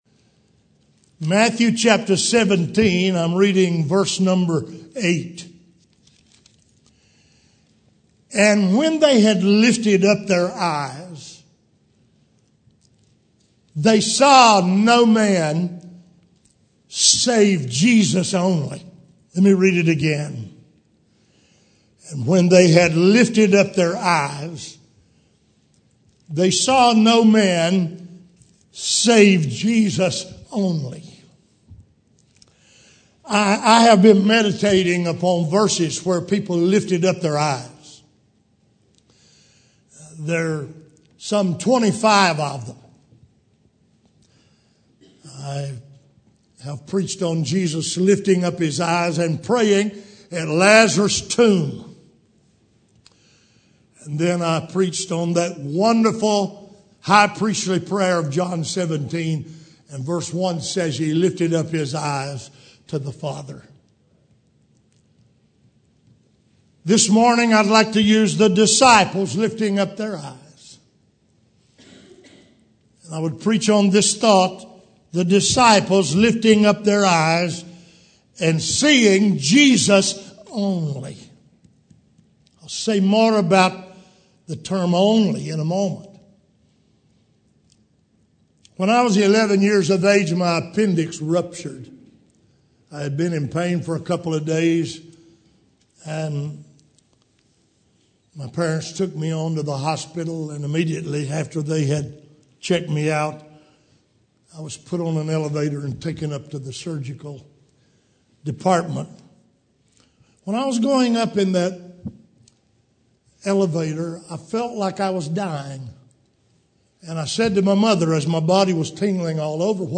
Audio Sermon Video Sermon Save Audio http